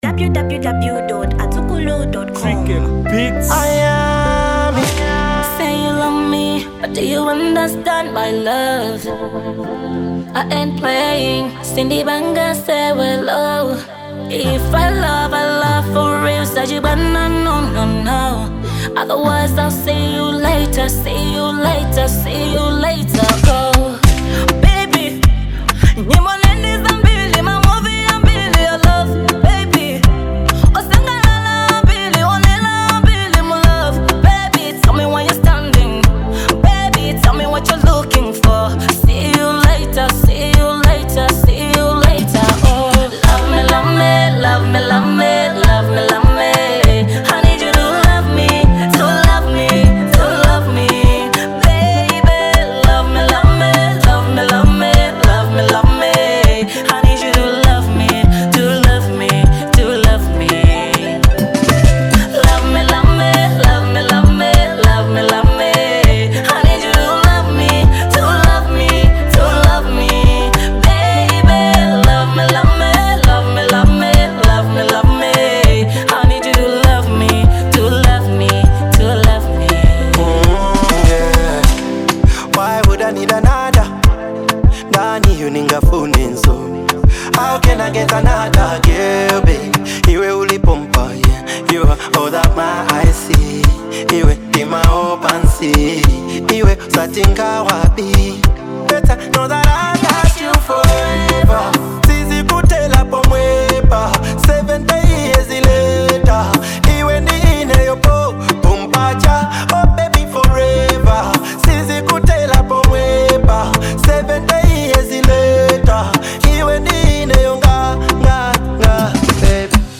Genre Afrobeat